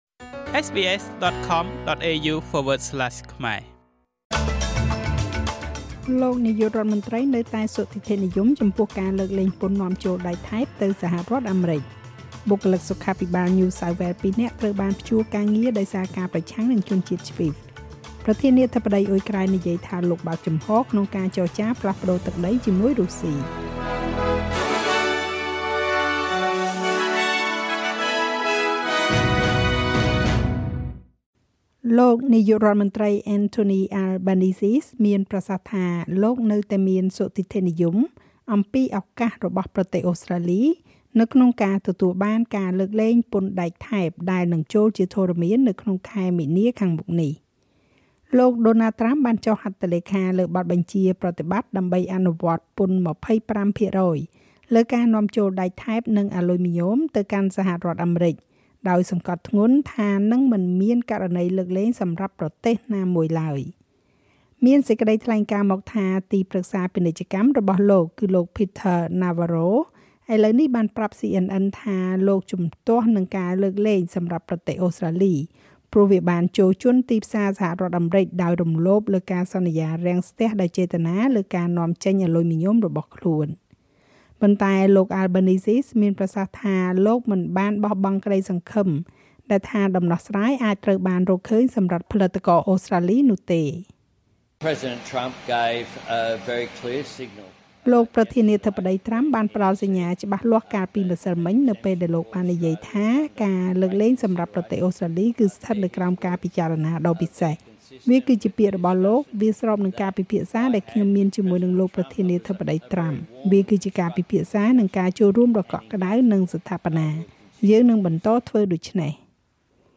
នាទីព័ត៌មានរបស់SBSខ្មែរ សម្រាប់ថ្ងៃពុធ ទី១២ ខែកុម្ភៈ ឆ្នាំ២០២៥